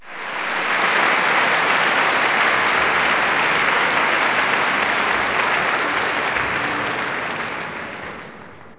clap_036.wav